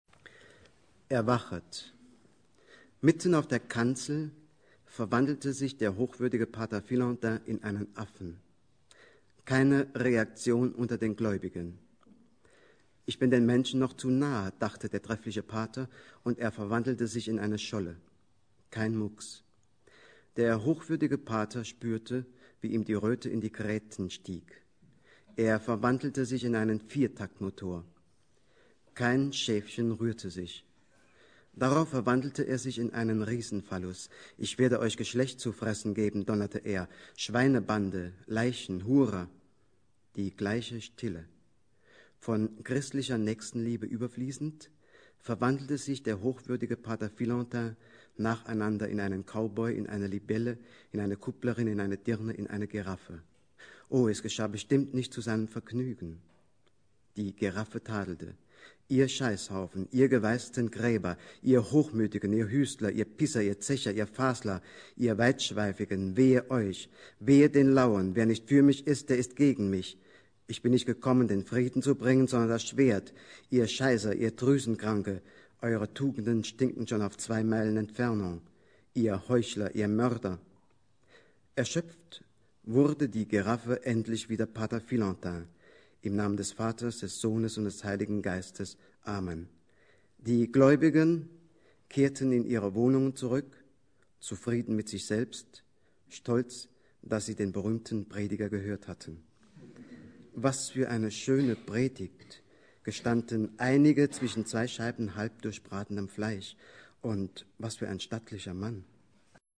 Begegnung in Saarbrücken